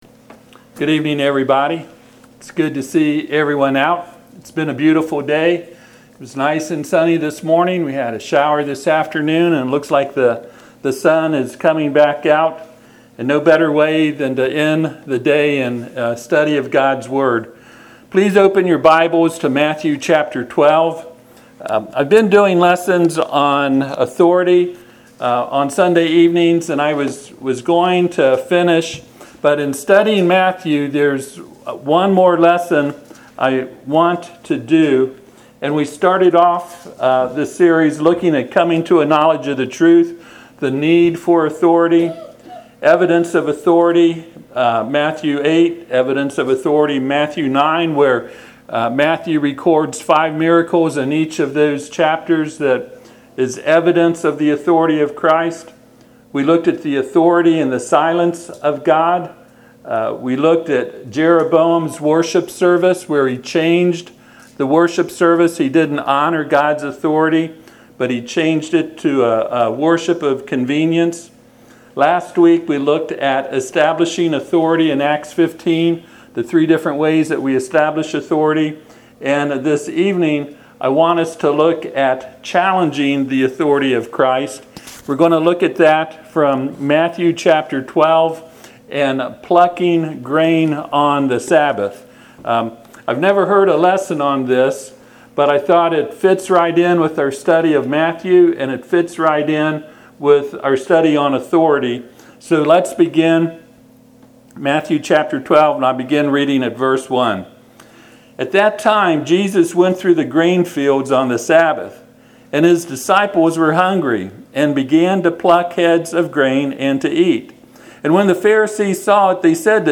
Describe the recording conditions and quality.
Passage: Matthew 12:1-16 Service Type: Sunday PM « Father’s Day Gifts To Give Your Children Why Was The Good Hand Of God On Ezra?